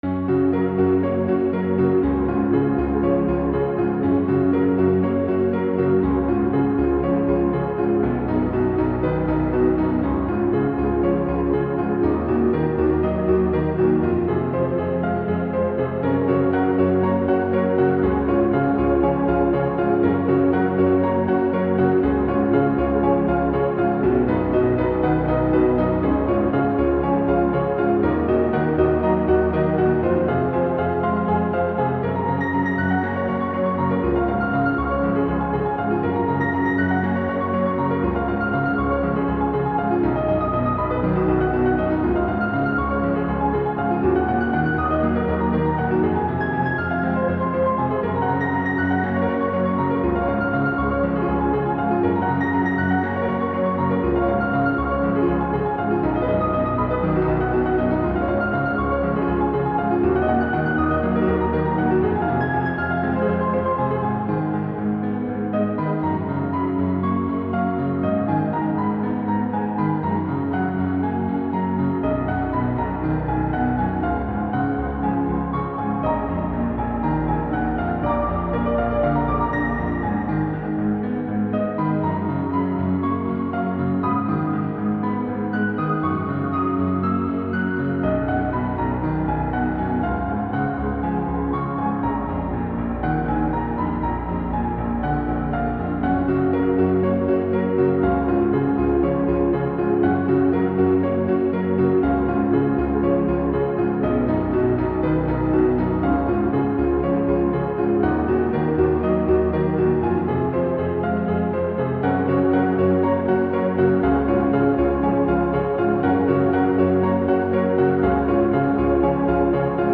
Made in FL Studio, reverbed with Valhalla Supermassive.
solo instrument